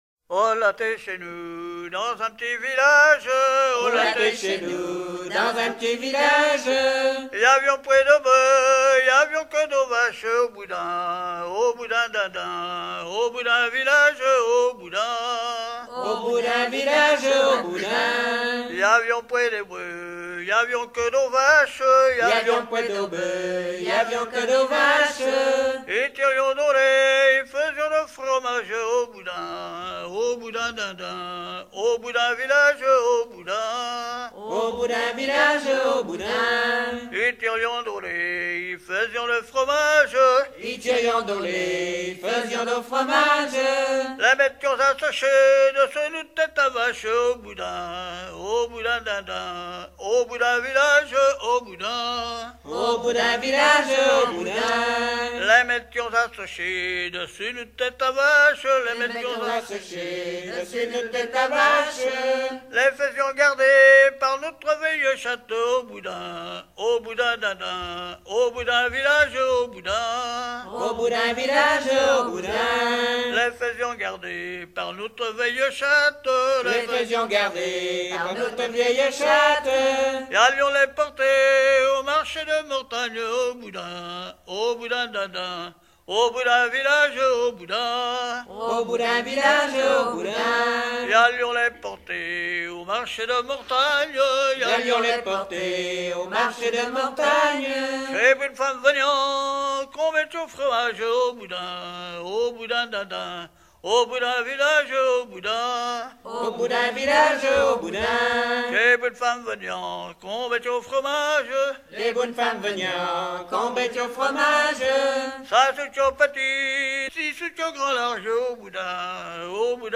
Langue Patois local
Genre laisse